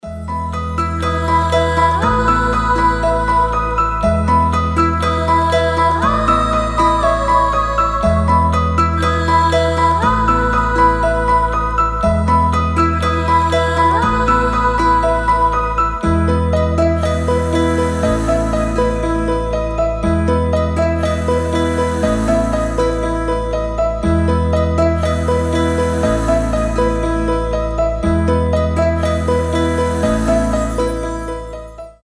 • Качество: 320, Stereo
мелодичные
легкие
Легкая, мягкая инструментальная музыка